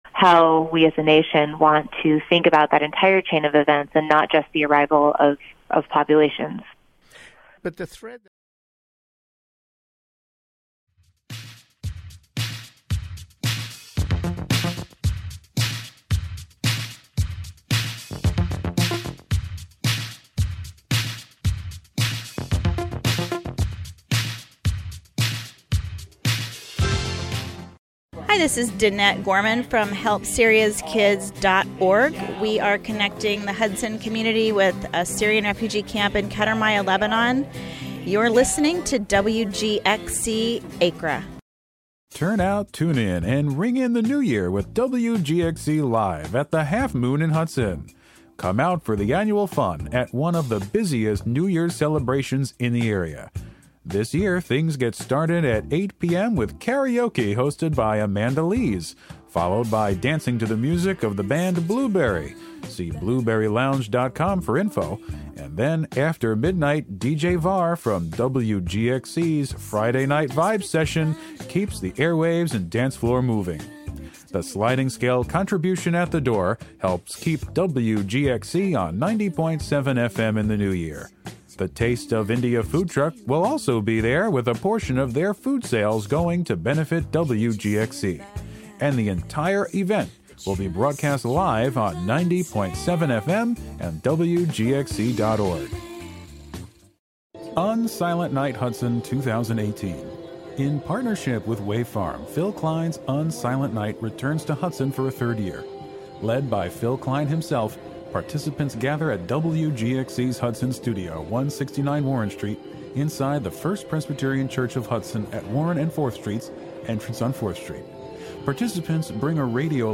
"All Together Now!" is a daily news show covering...
"All Together Now!" is a daily news show covering radio news, and news about the Hudson Valley. The show is a unique, community-based collaboration between listeners and programmers, both on-air and off. "All Together Now!" features local and regional news, weather updates, feature segments, and newsmaker interviews.